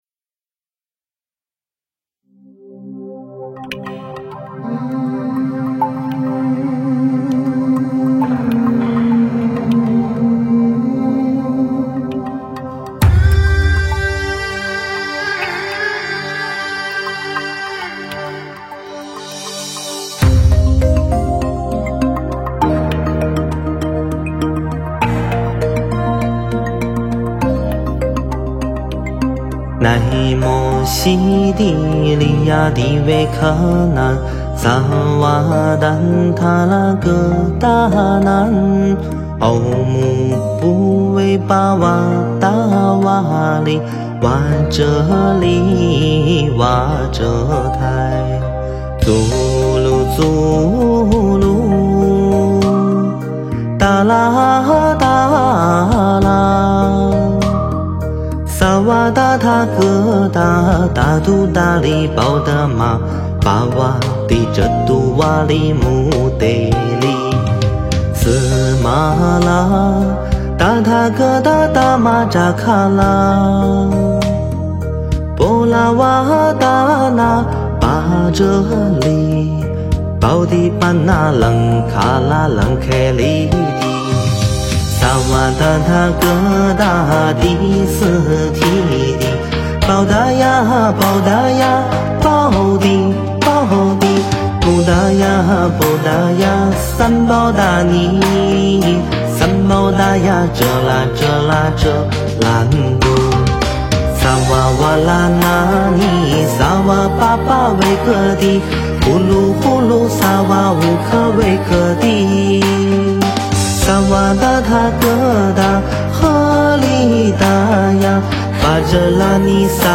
佛音 凡歌 佛教音乐 返回列表 上一篇： 心无罣碍(梵文唱诵-轻快版